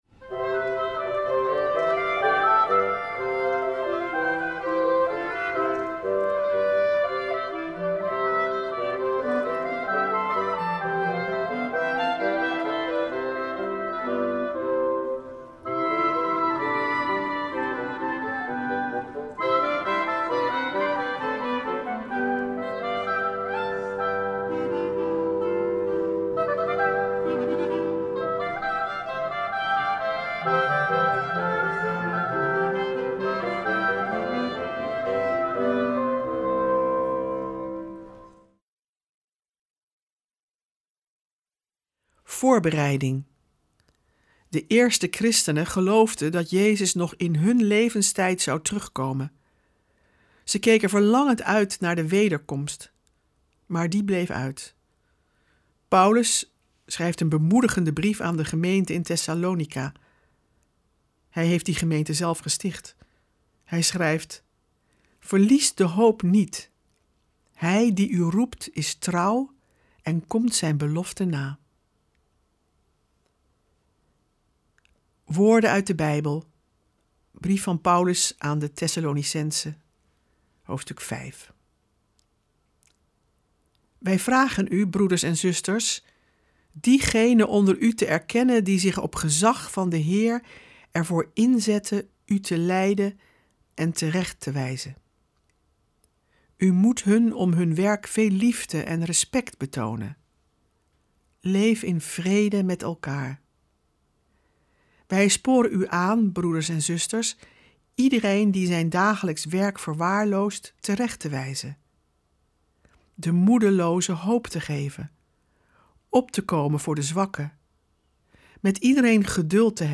We verbinden ze met onze tijd en we luisteren naar prachtige muziek en poëzie.